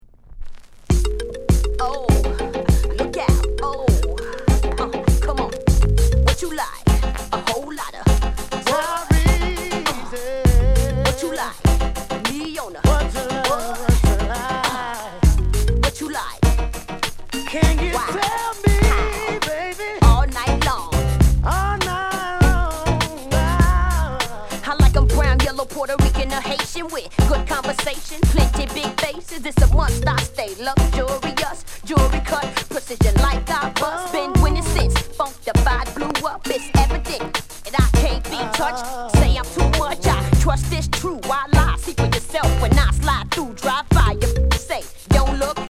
SOUND CONDITION A SIDE VG